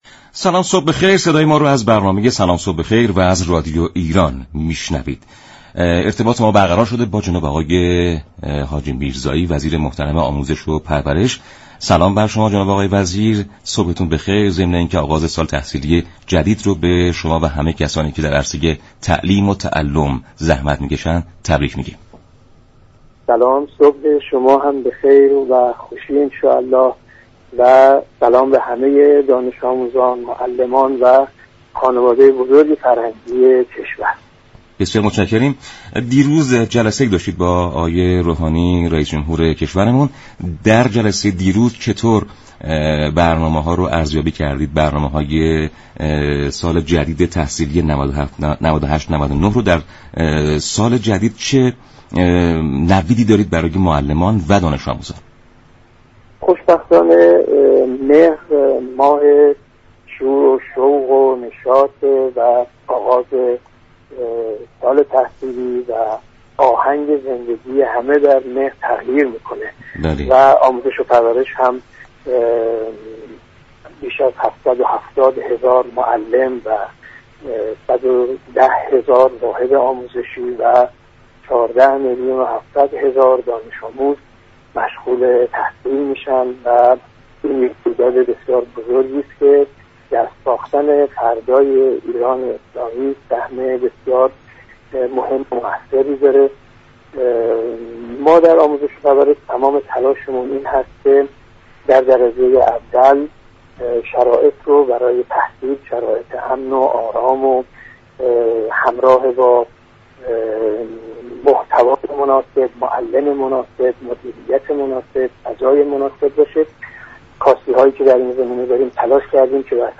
محسن حاجی میرزایی وزیر آموزش و پرورش در گفت و گو با «سلام صبح بخیر» گفت: اكثر معلمان مدارس ایران به استخدام وزارت آموزش و پرورش در آمده اند.